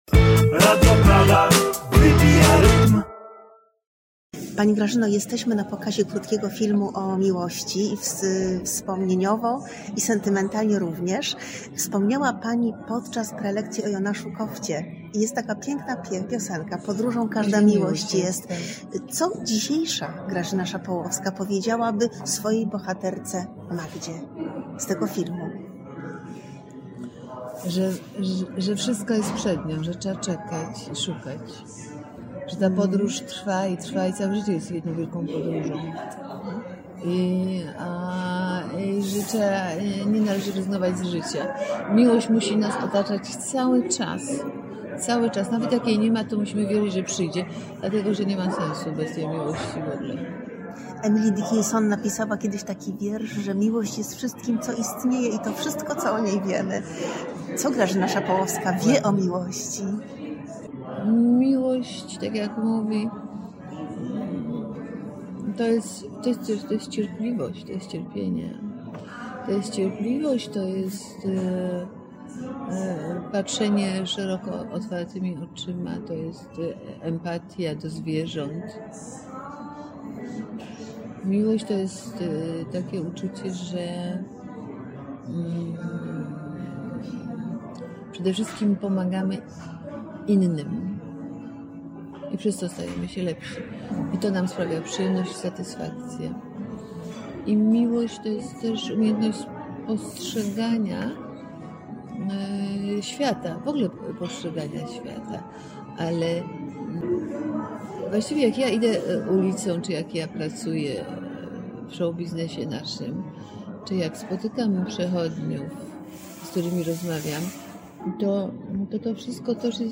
Powiedziała to (i nie tylko to:)) podczas rozmowy dla Radia Praga.
„Filmowe obrazy: Witold Adamek” odbyło się 17 marca 2025 w warszawskim kinie Kultura.
Grazyna-Szapolowska-dla-Radia-Praga.mp3